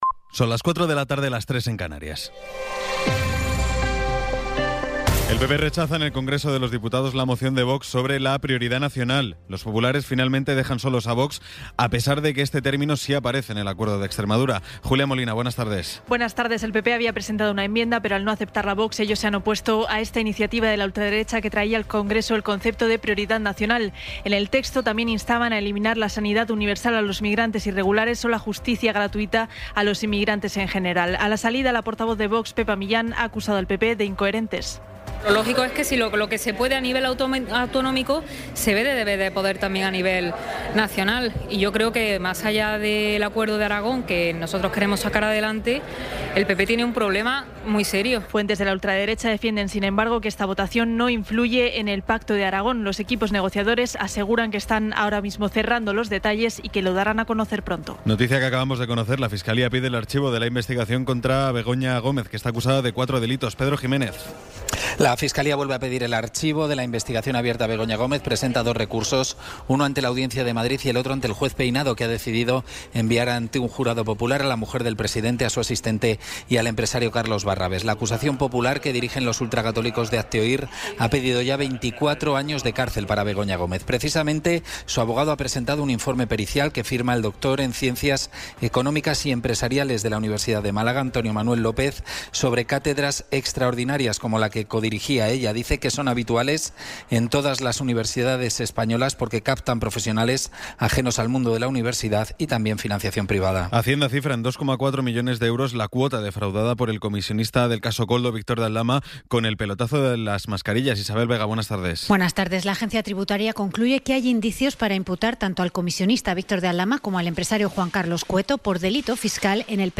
Resumen informativo con las noticias más destacadas del 22 de abril de 2026 a las cuatro de la tarde.